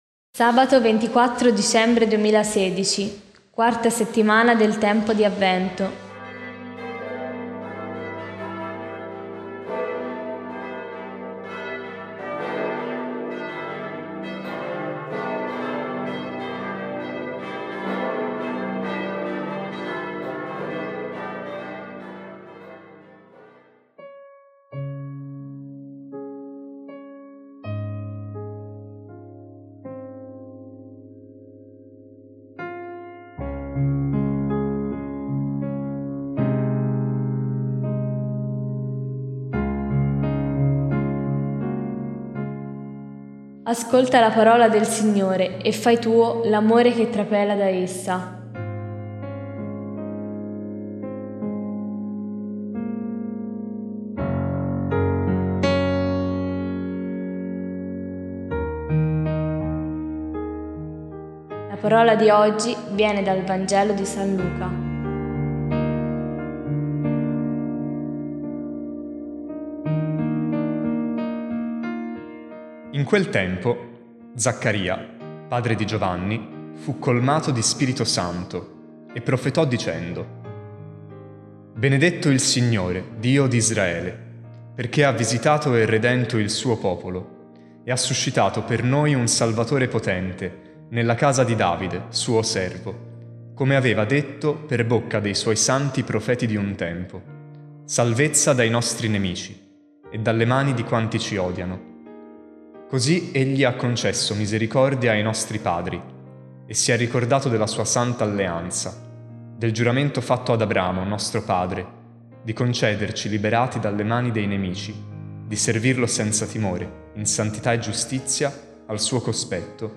Musica: Adeste Fideles